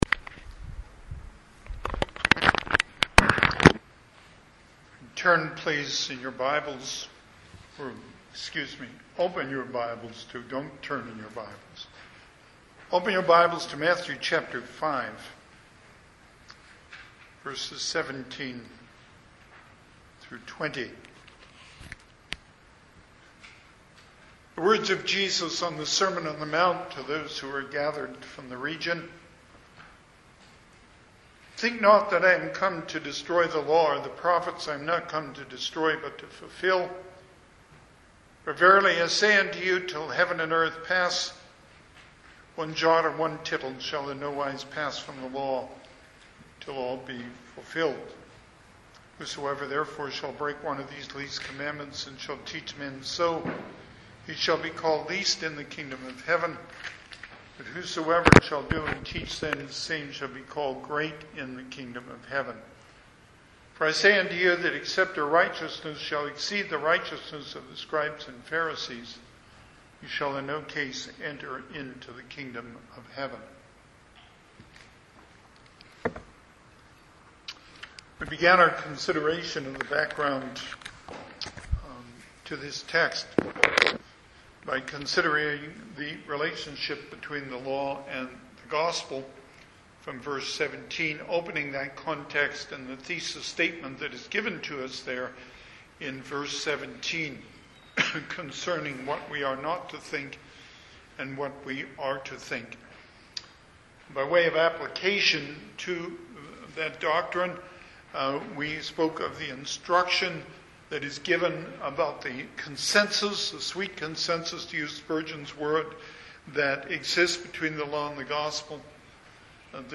Passage: Matthew 5 Service Type: Sunday AM